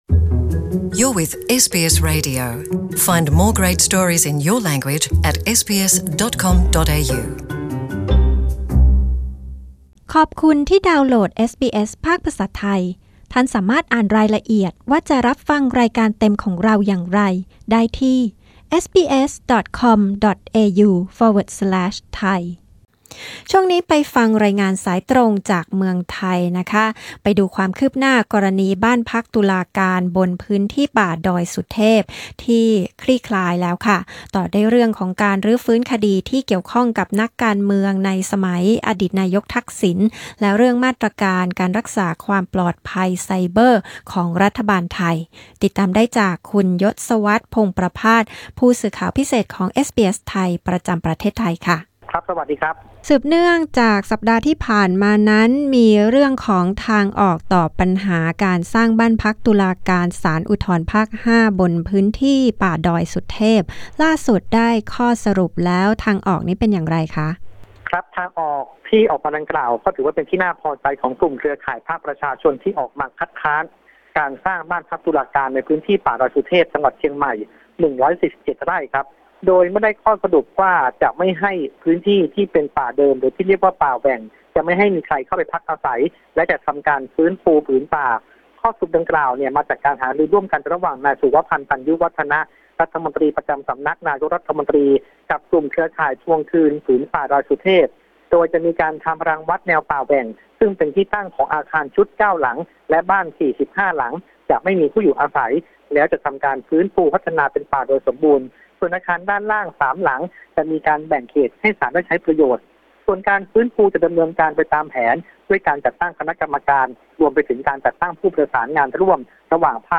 กดด้านบนเพื่อฟังสรุปข่าว